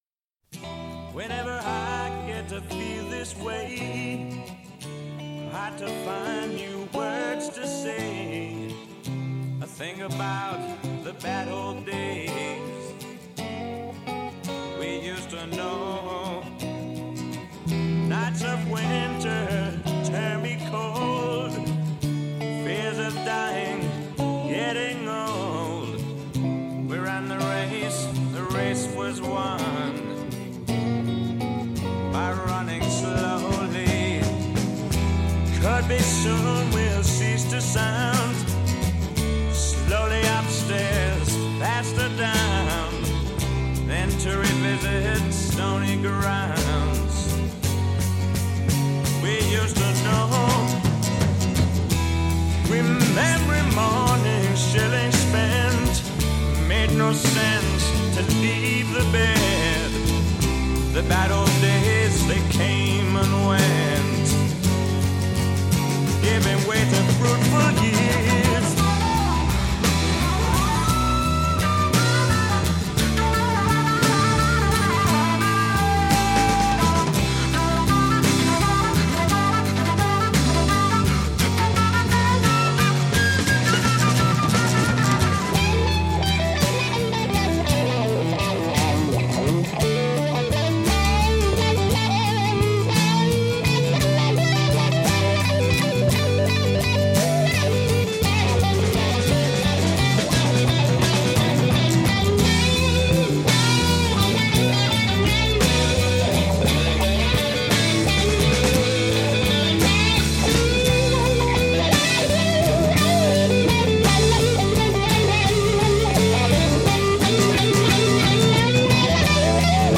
he reportedly wrote “Hotel California” in E minor